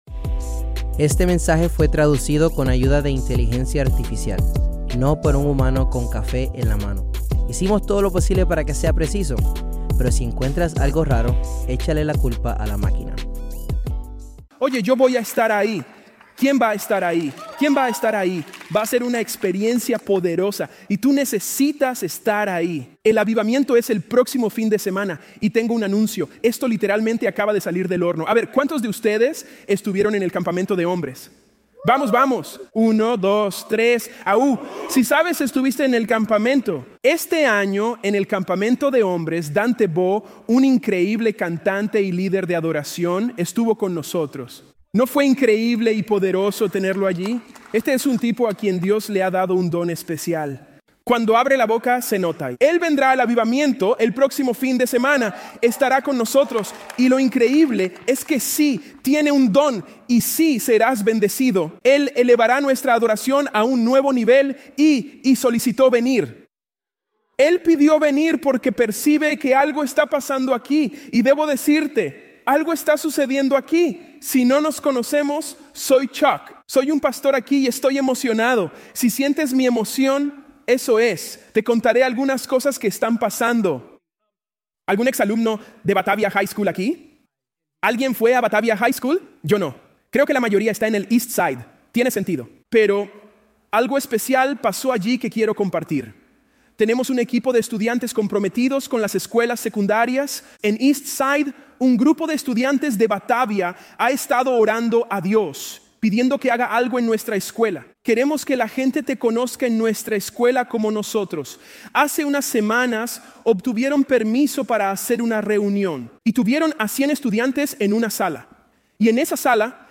Grabado en vivo en Crossroads Church, en Cincinnati, Ohio.